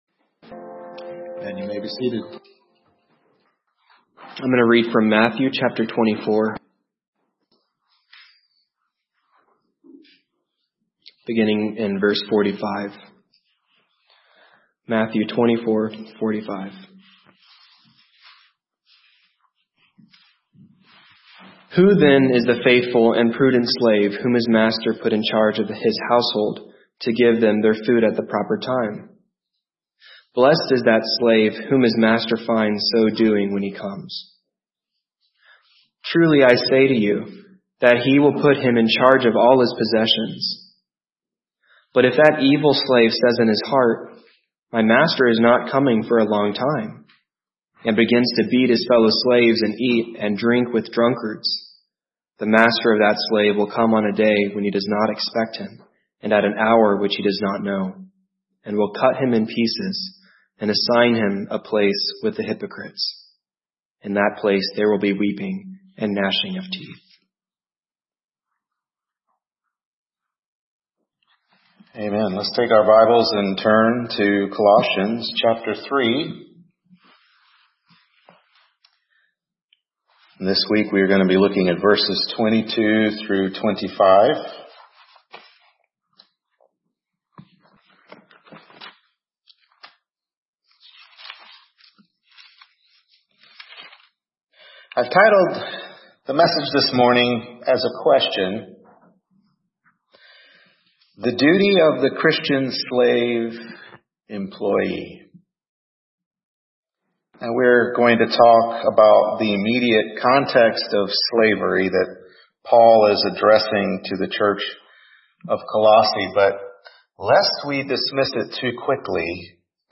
Colossians 3:22-25 Service Type: Morning Worship Service Colossians 3:22-25 The Duty of the Christian Slave/Employee?